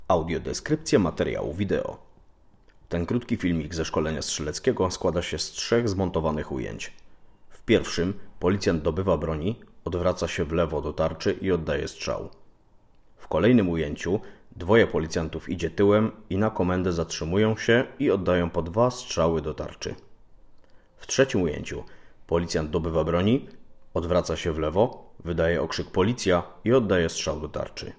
Nagranie audio Audiodeskrypcja do filmu Szkolenie strzeleckie świętochłowickich mundurowych